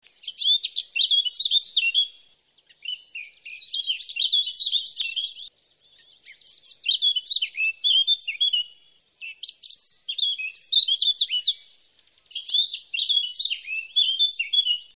Zwartkop
Zwartkop.mp3